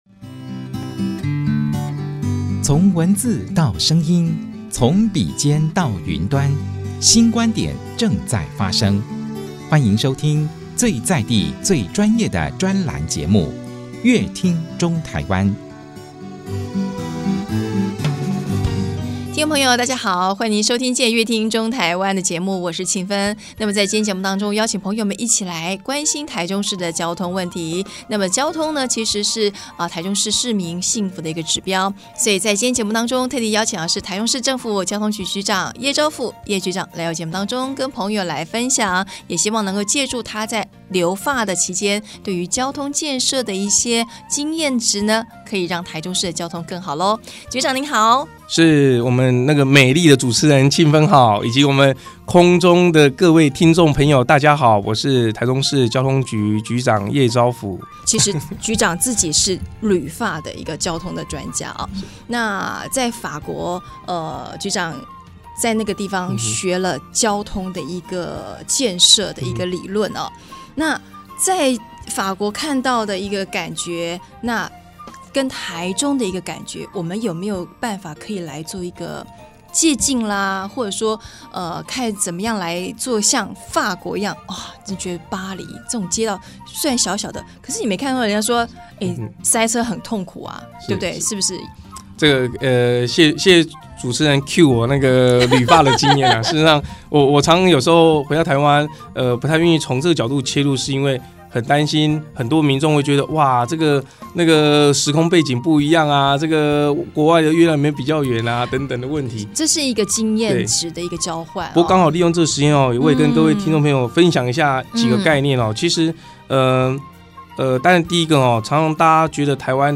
本集來賓：台中市政府交通局葉昭甫局長 本集主題：「借鏡法國交通經驗擘劃台中幸福城市願景」 本集內容： 大家常會